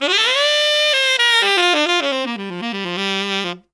Index of /90_sSampleCDs/Zero-G - Phantom Horns/SAX SOLO 2